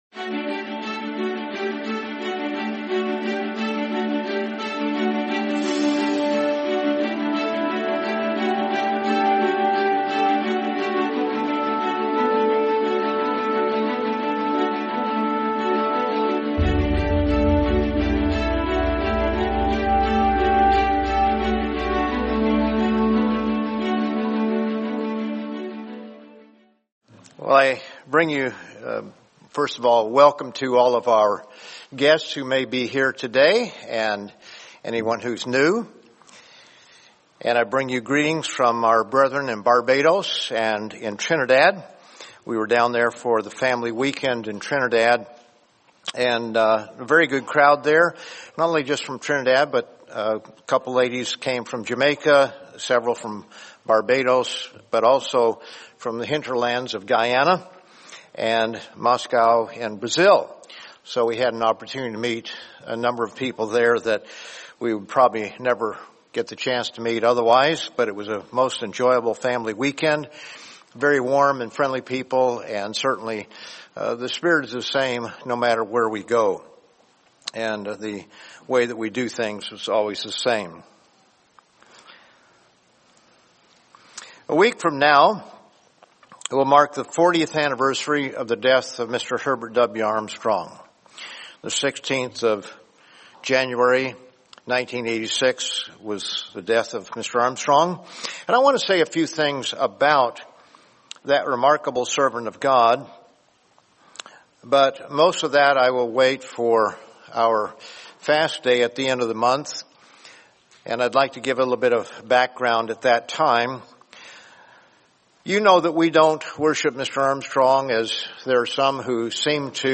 | Sermon | LCG Members